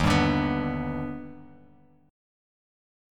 D#+M7 chord